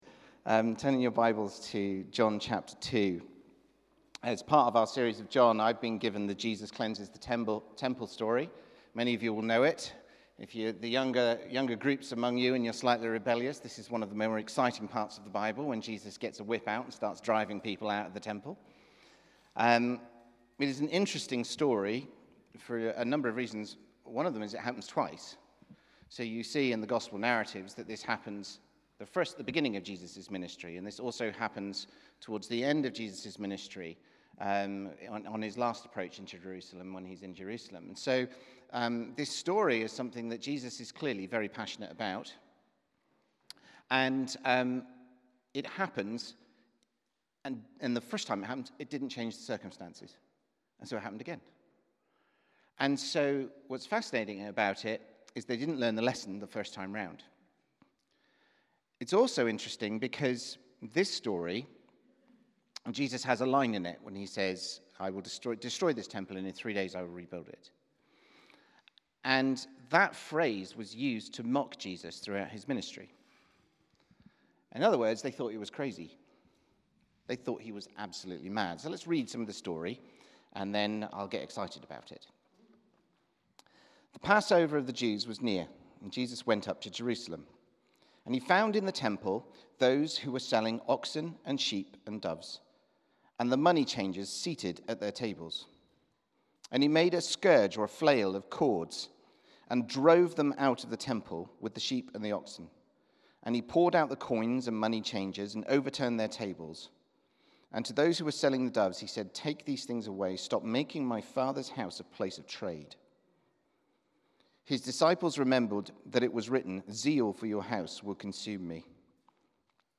Sermon - John 2:13-22